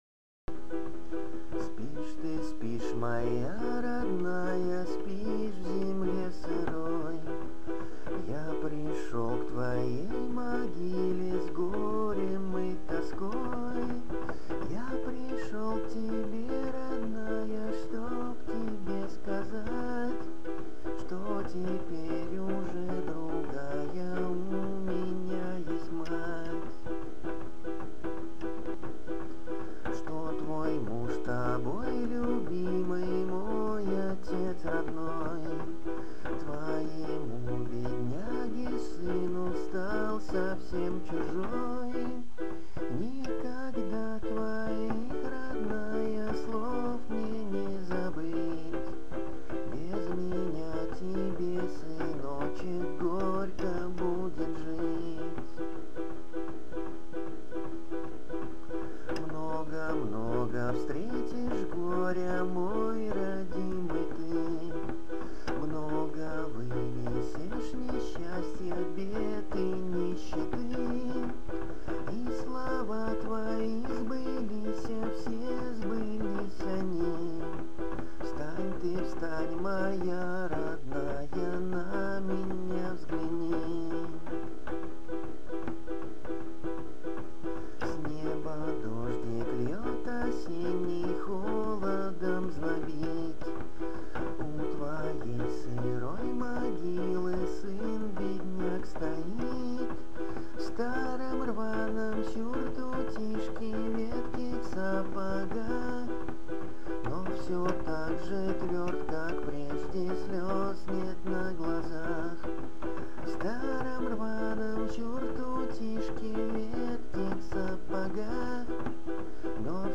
Песни на стихотворение: